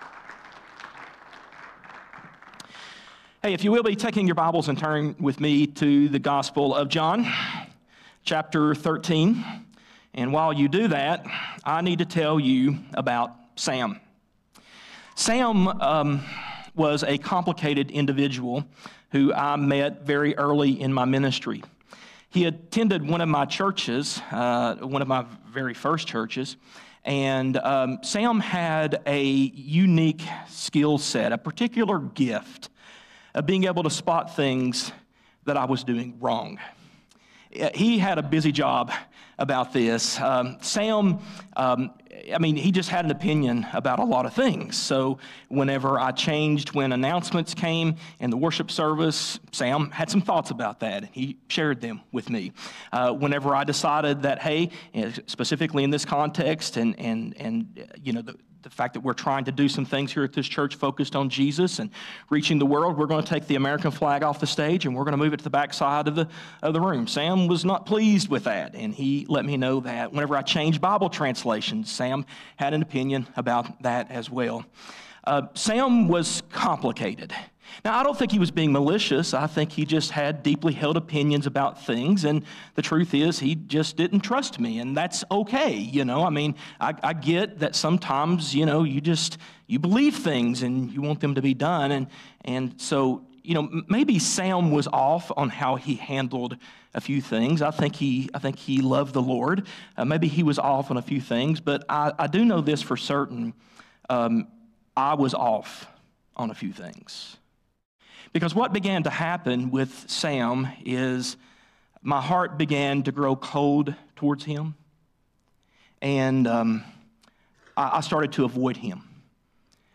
A message from the series "Re-Gifting."